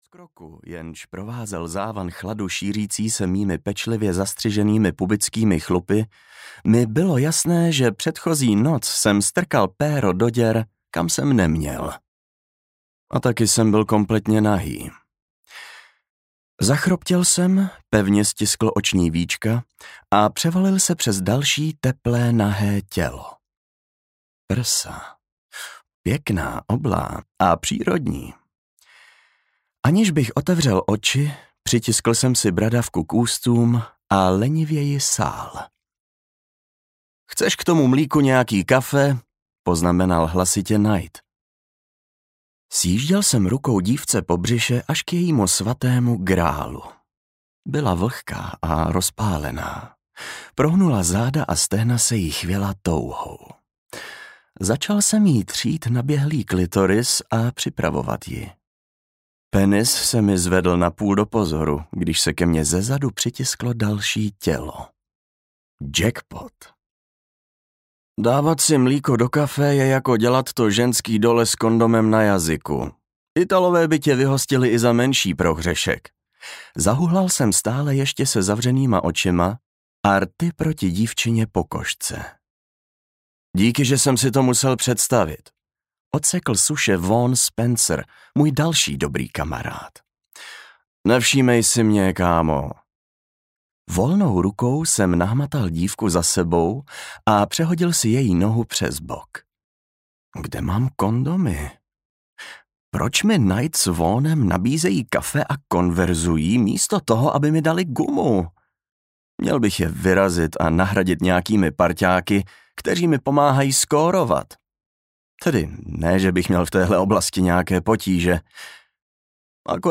Lovec audiokniha
Ukázka z knihy